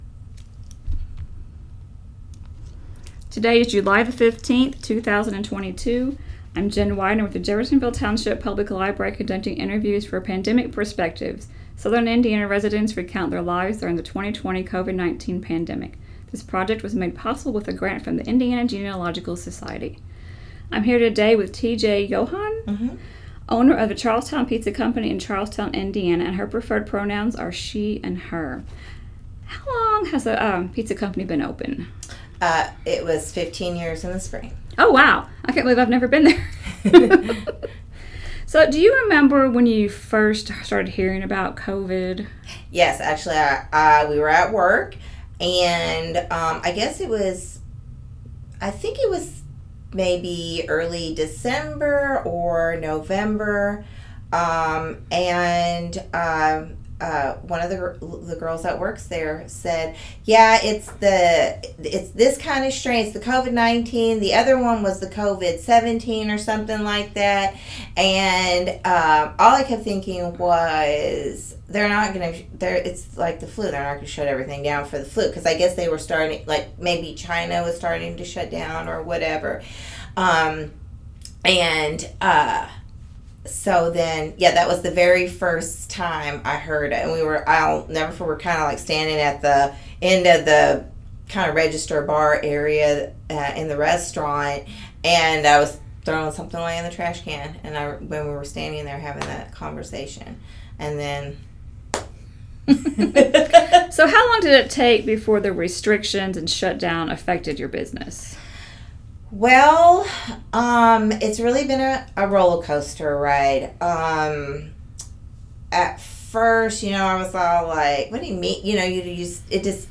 Oral Histories As the Covid-19 pandemic progressed and continued the need to capture people's stories grew.